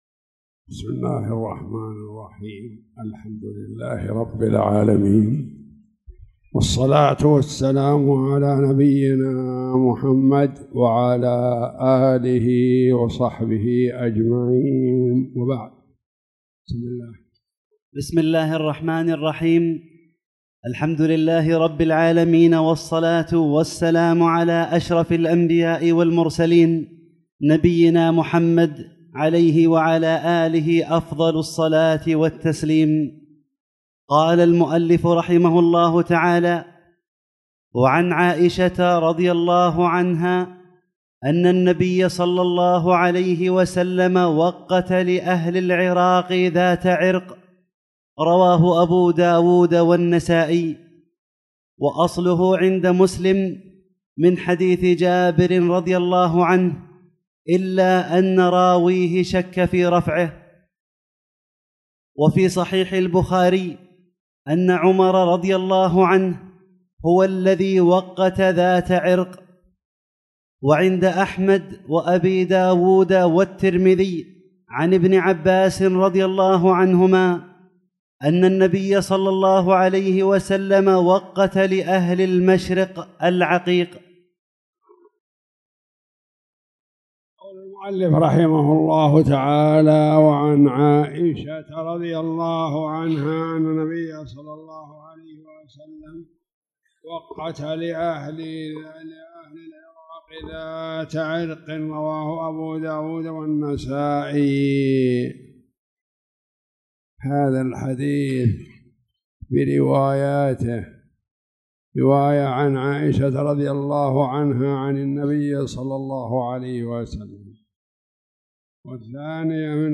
تاريخ النشر ١٧ ربيع الثاني ١٤٣٨ هـ المكان: المسجد الحرام الشيخ